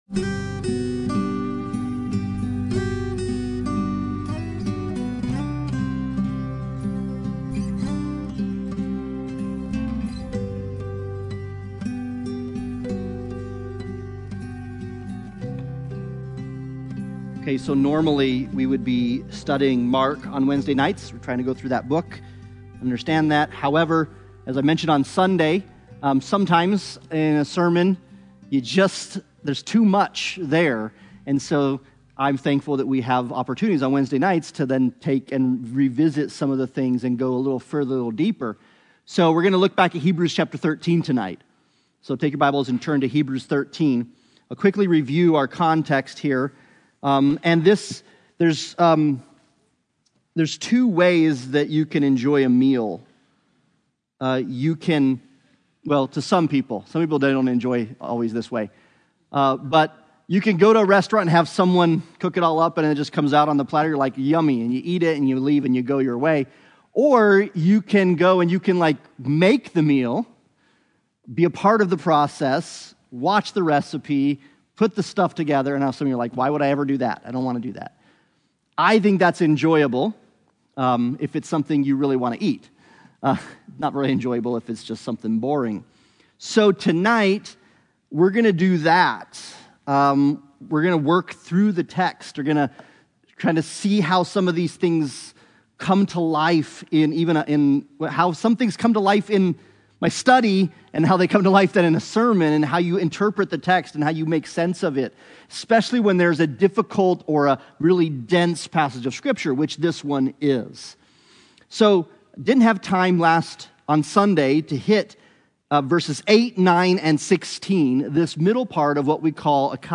Service Type: Sunday Bible Study